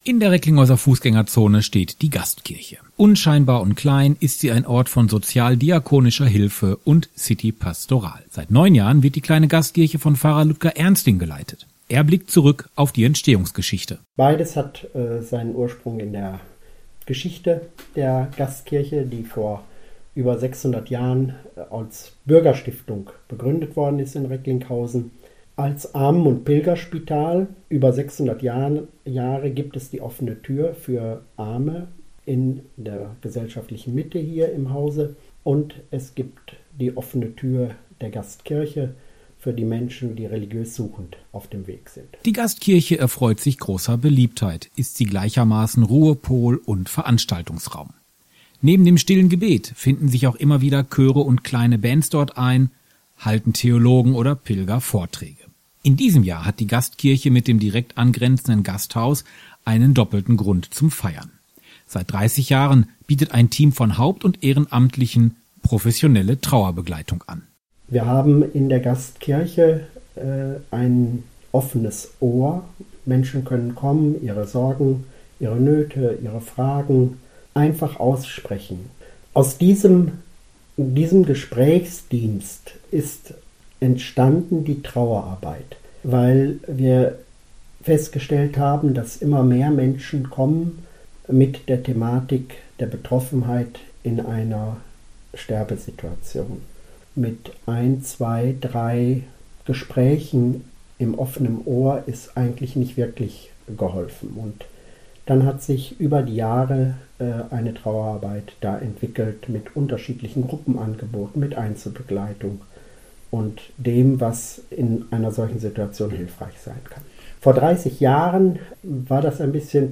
Interviewmitschnitt 30 Jahre Trauerbegleitung